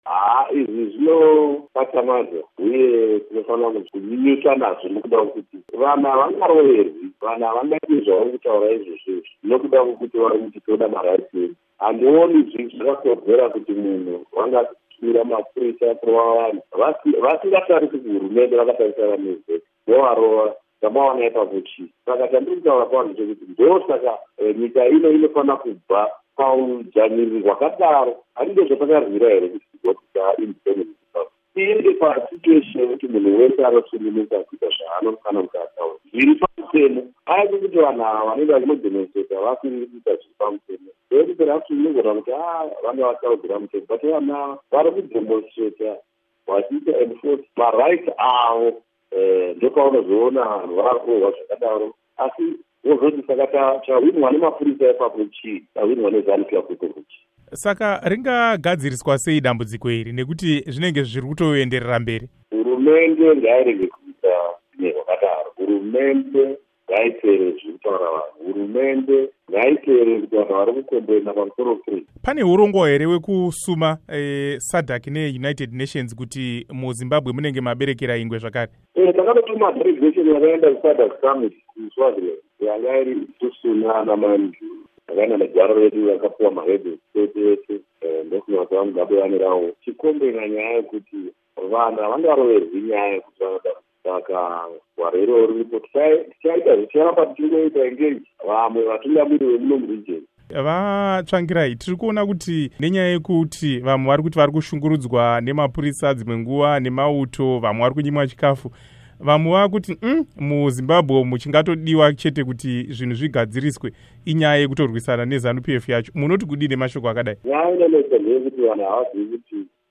Hurukuro naVaMorgan Tsvangirai